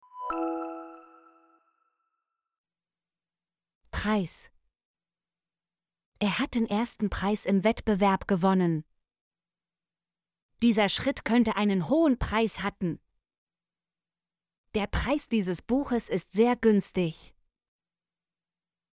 preis - Example Sentences & Pronunciation, German Frequency List